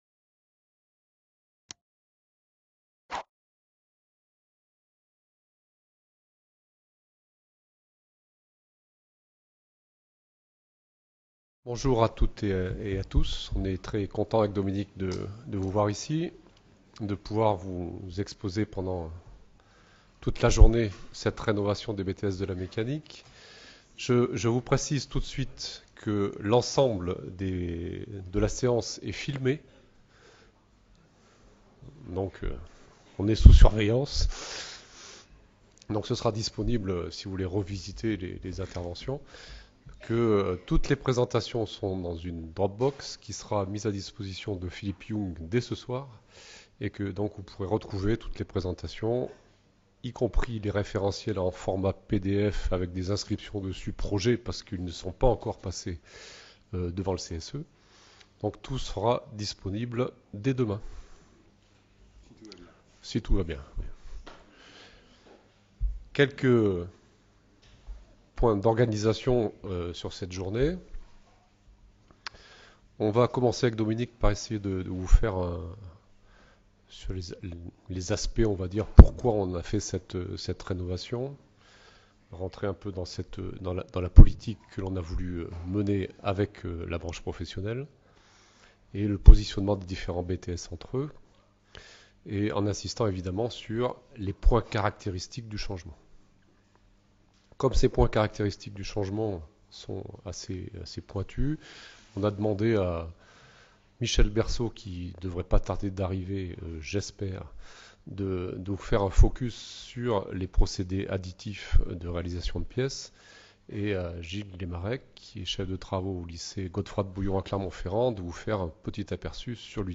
VIDEO : partie 1 Ce séminaire national du 8 décembre 2015, est destiné aux inspecteurs d’académie - inspecteurs pédagogiques régionaux en sciences et technologies industrielles, aux chefs de travaux et professeurs impliqués dans ces formations de brevets de technicien supérieur. Thèmes abordés : Rénovation des brevets de technicien supérieur ; Industrialisation des Produits Mécaniques IPM ; Conception de Produits Industriels CPI ; Mise en forme des matériaux par forgeage et Fonderie.